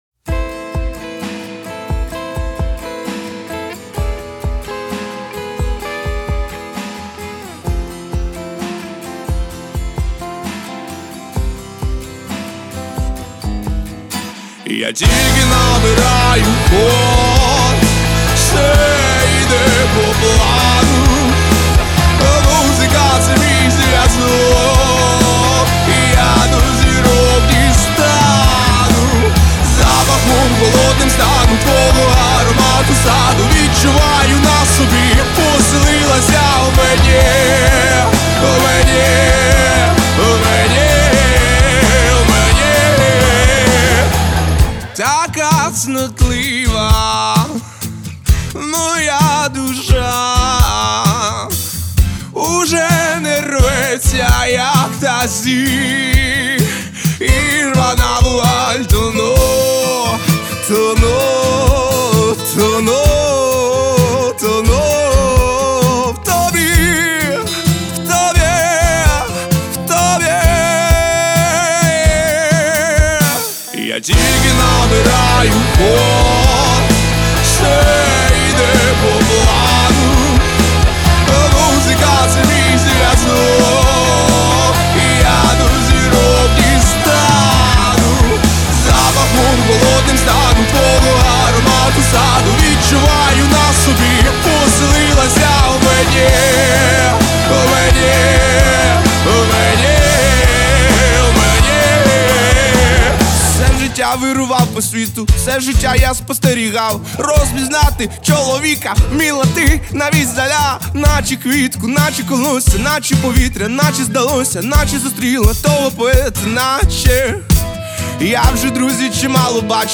потужний та серйозний трек